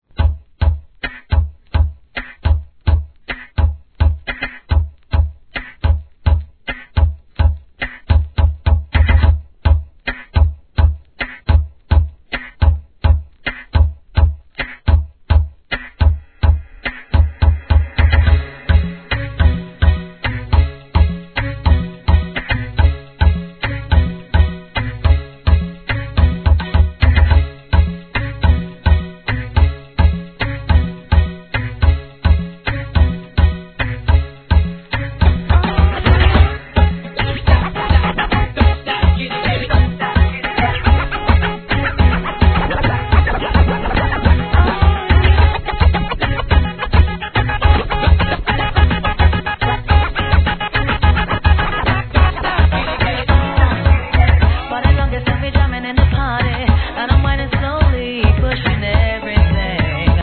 HIP HOP/R&B
レーベルお抱えのリエディター達が原曲をライトかつフロア仕様に変貌!